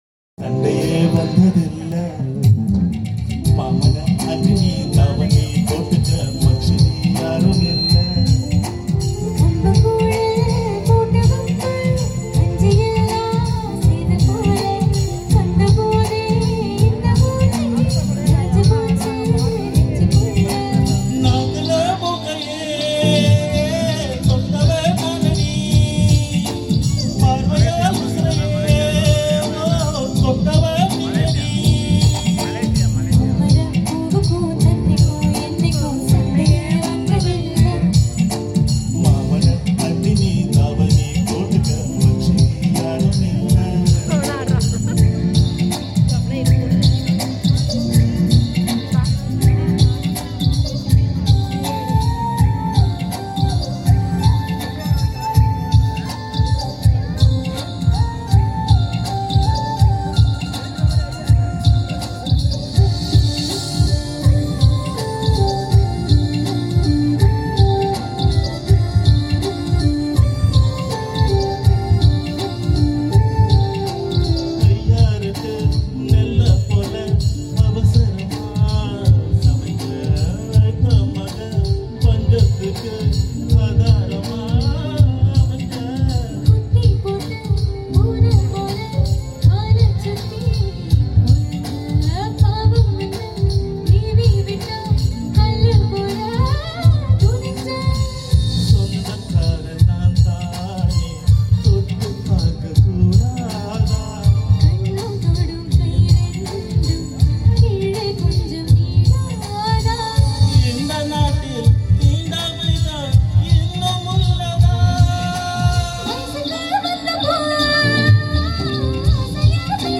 in Tanjavore India, on 15 MARCH 2025. Got a response from Indian audience.